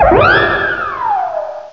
Cri de Farfaduvet dans Pokémon Noir et Blanc.